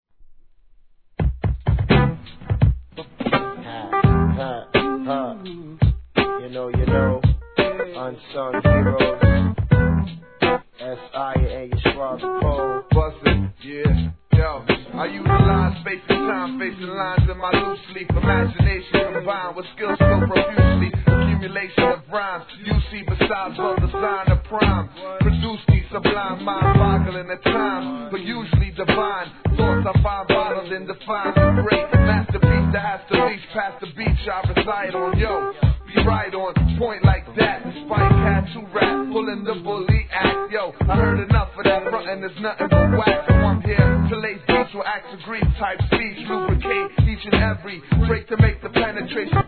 HIP HOP/R&B
TIGHTなBEATに無駄なく絡めたPIANOのフレーズ